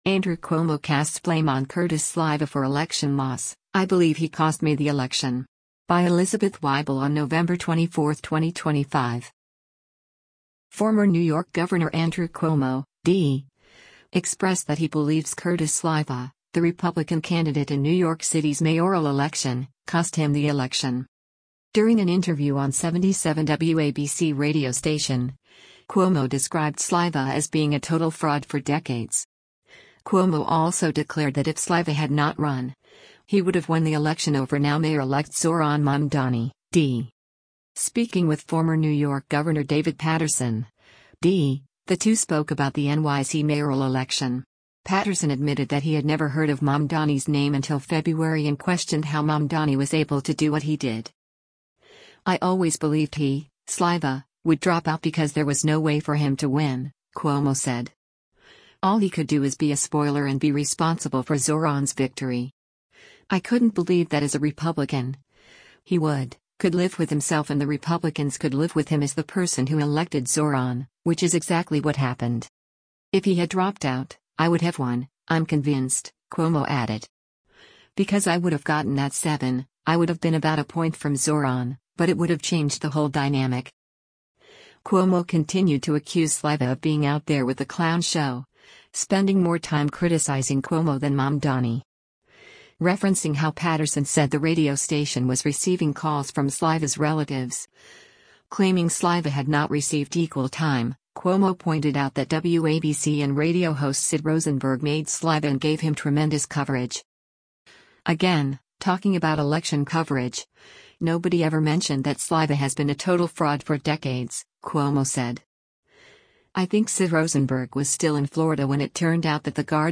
During an interview on 77 WABC radio station, Cuomo described Sliwa as being “a total fraud for decades.”
Speaking with former New York Gov. David Paterson (D), the two spoke about the NYC mayoral election.